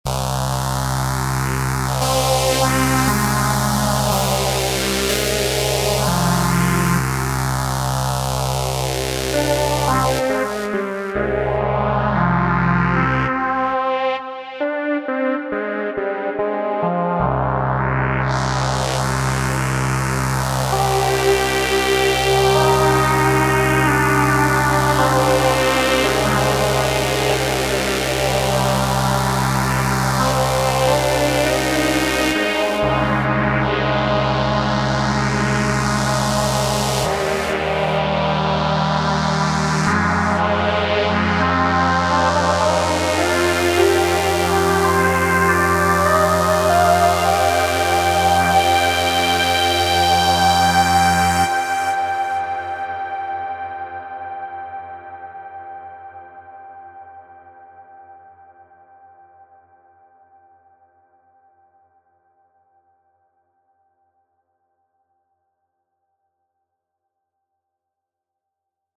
Ok ich schalte mein Montage ein und mache einen Pad Preset in der vintage Richtung. Und hier ist eine Demo zuerst sehr heller Klang, der Filter ist offen und aus Effekten sind interne Phaser + Chorus + Delay im Spiel. Danach der Filter geht etwas zu und ich aktiviere zu allen Effekten interner Reverb mit sehr langem 30 Sekunden Decay.